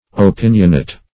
Opinionate \O*pin"ion*ate\, a.
opinionate.mp3